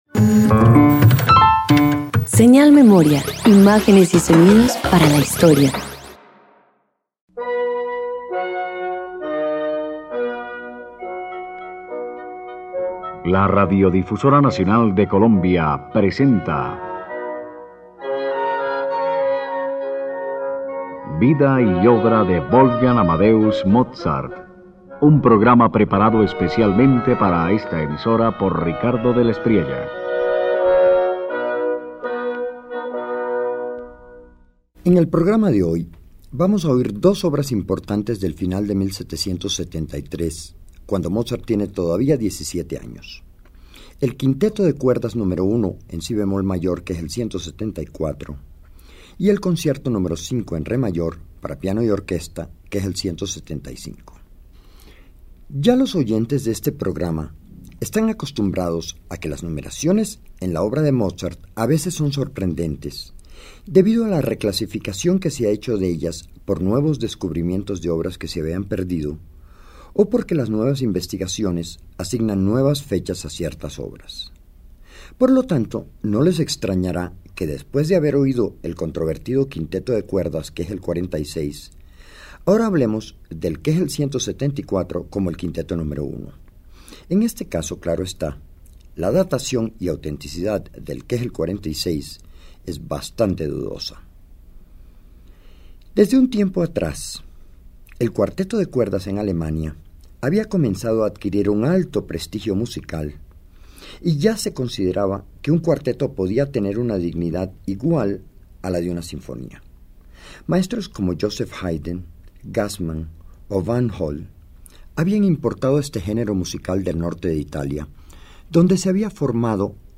Paralelamente, el Concierto No. 5 para piano y orquesta en re mayor evidencia su brillante y concertante escritura.
Radio colombiana